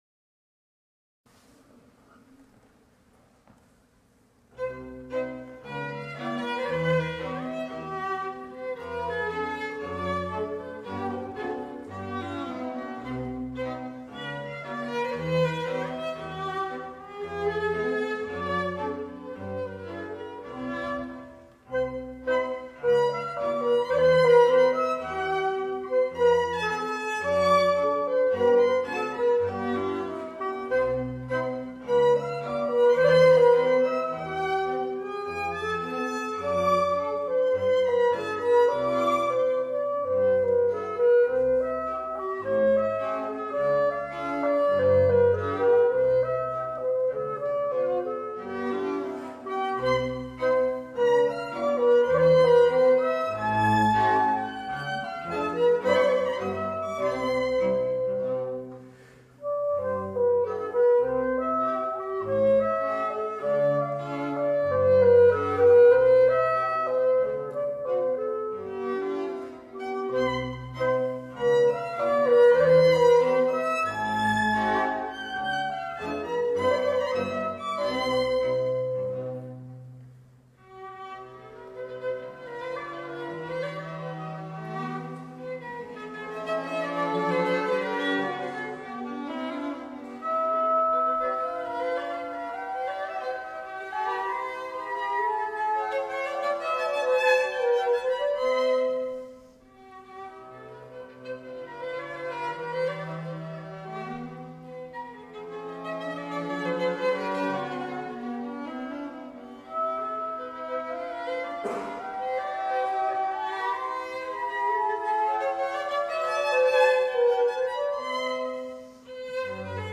Octeto-en-fa-mayor-D-803-IV-Andante-Orquesta-de-Schubert-Piano-Clasic.mp3